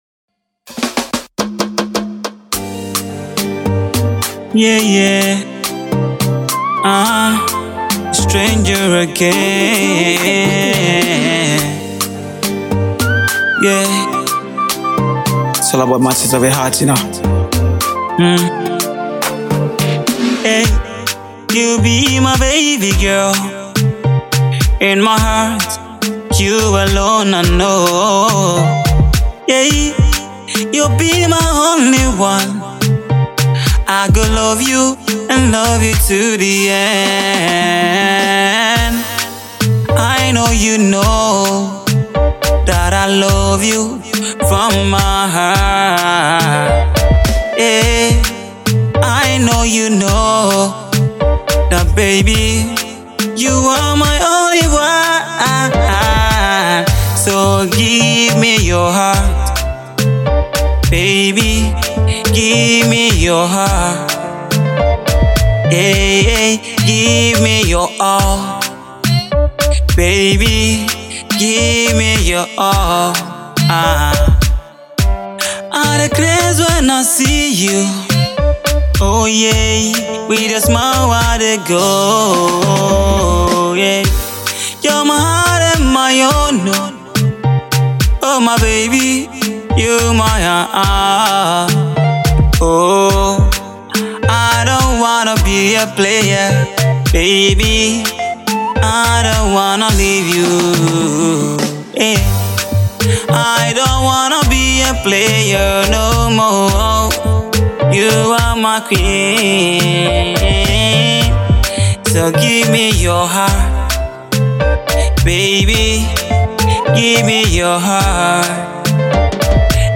singer and rapper